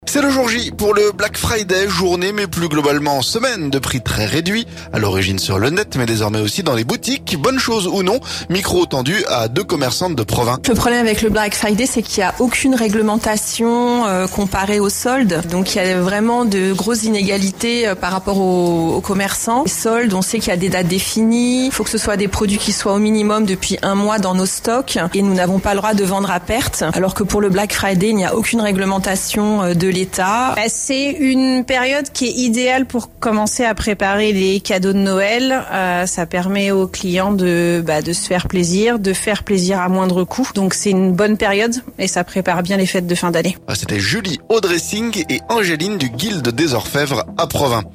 Bonne chose ou non ? Micro tendu à deux commerçantes de Provins.